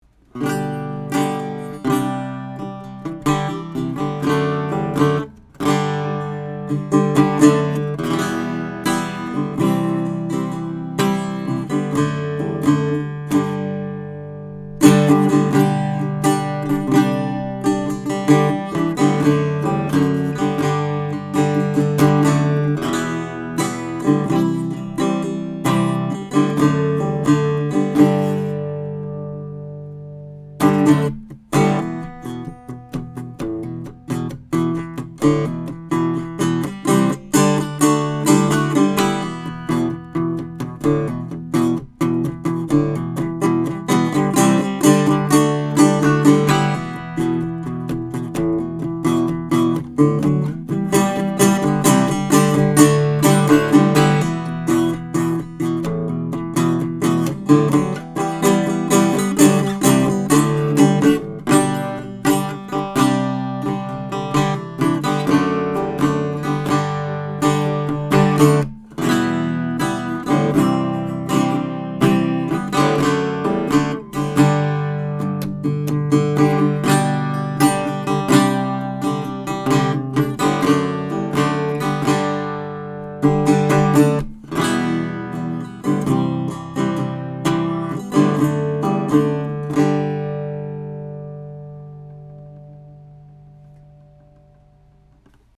RN17 going into a Trident 88 console to Metric Halo ULN-8 converters:
1930 WARDS AIRLINE FLATTOP